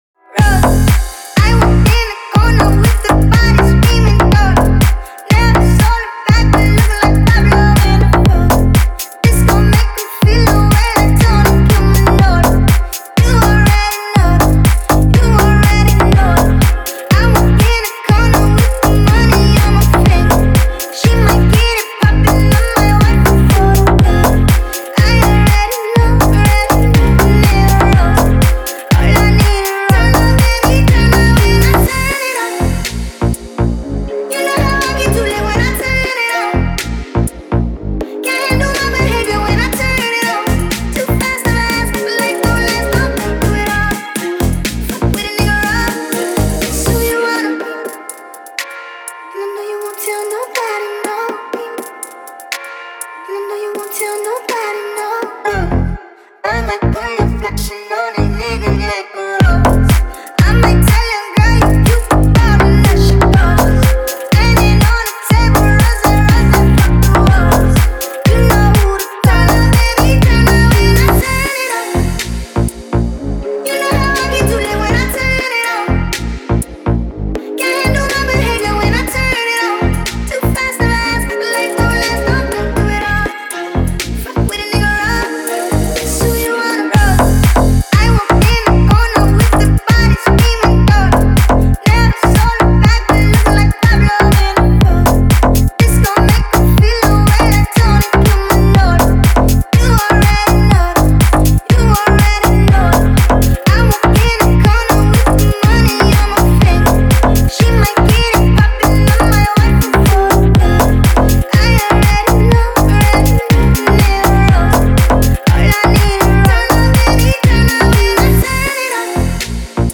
это яркий трек в жанре EDM и хип-хоп